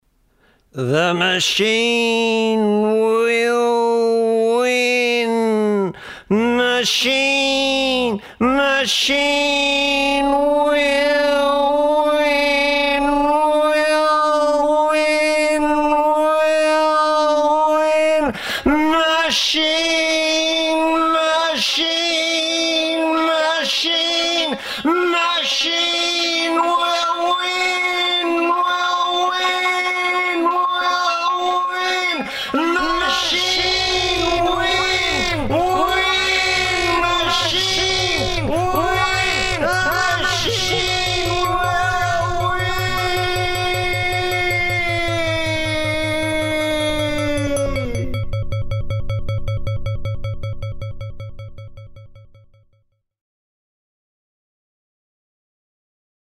Poetry, close to the edge and beyond, featuring :